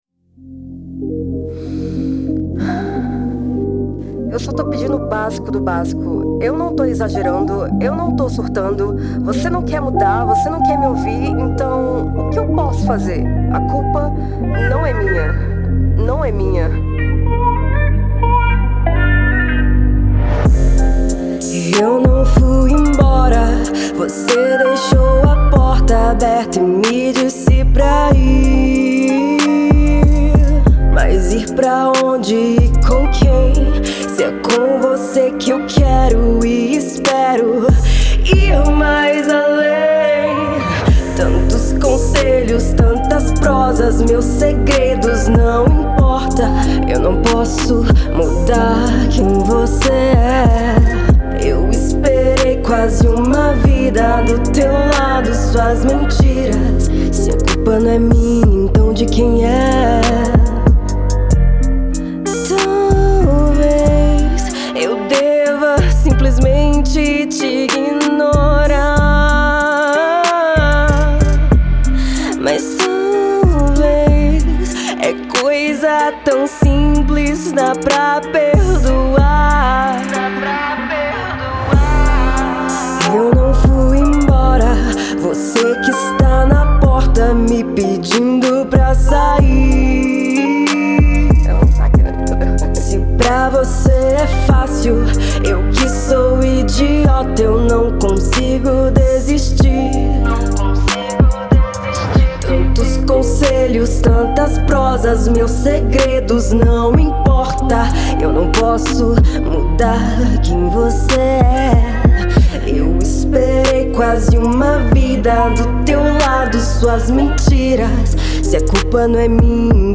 Gênero R&B.